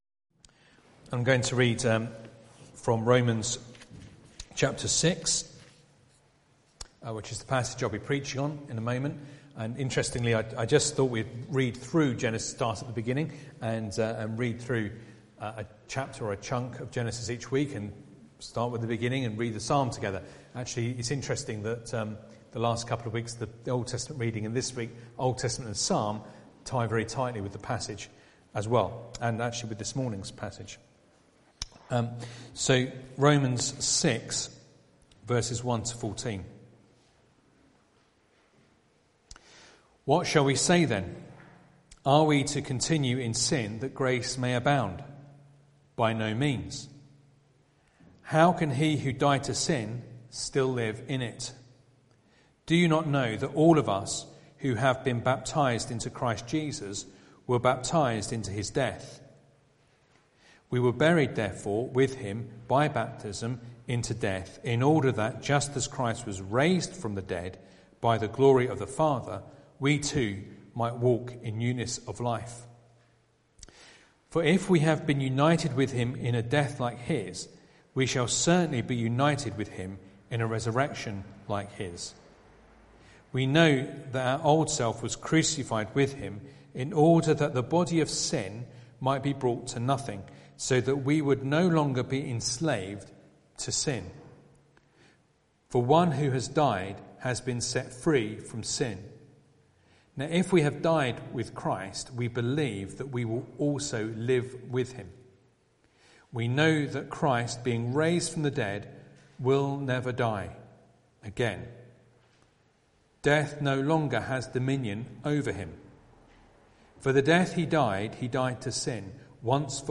Sunday Evening Reading and Sermon Audio